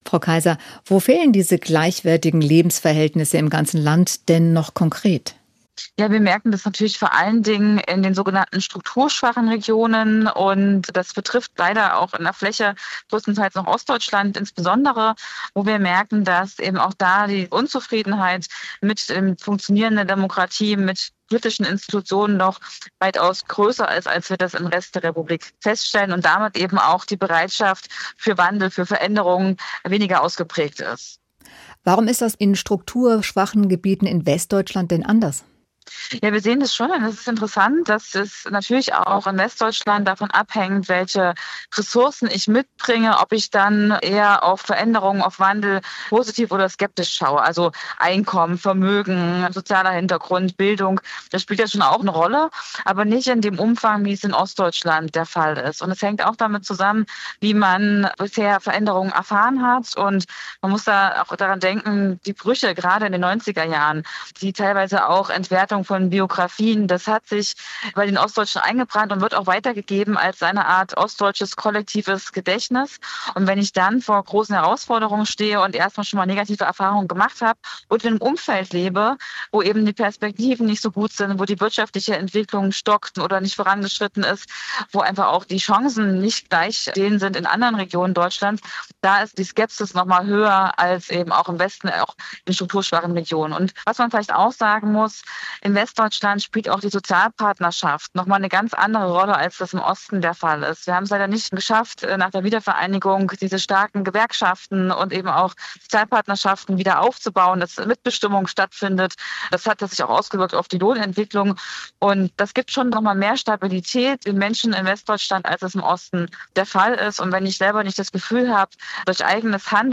deutschland-monitor-erfahrungen-praegen-blick-auf-veraenderung-ostbeauftragte-bundesregierung-kaiser.mp3